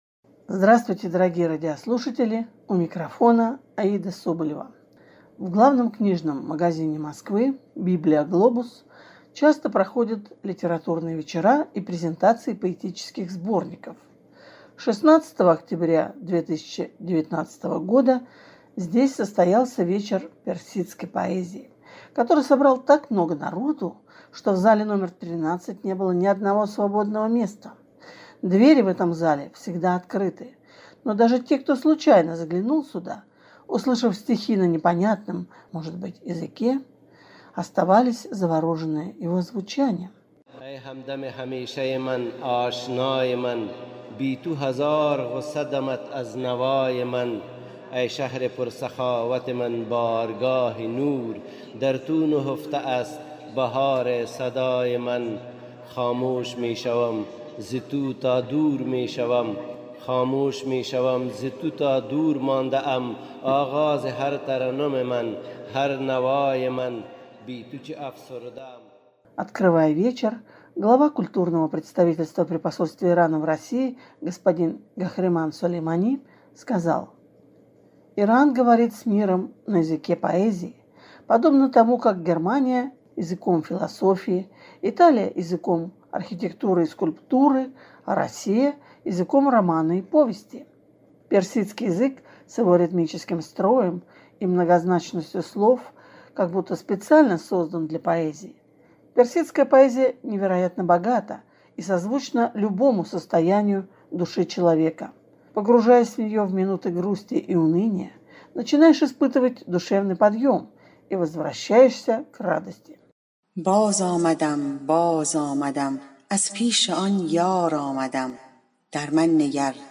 В главном книжном магазине Москвы «БИБЛИО-ГЛОБУС» часто проходят литературные вечера и презентации поэтических сборников.